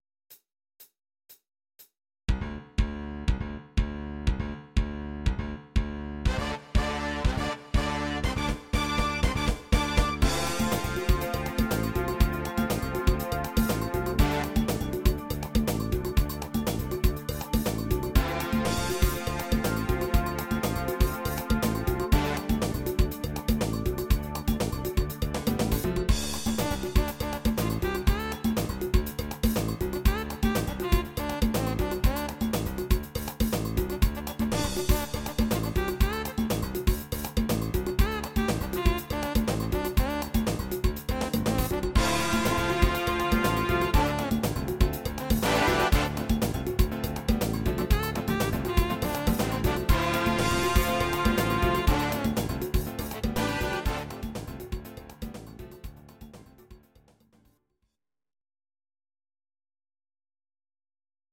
These are MP3 versions of our MIDI file catalogue.
Please note: no vocals and no karaoke included.
Your-Mix: Jazz/Big Band (731)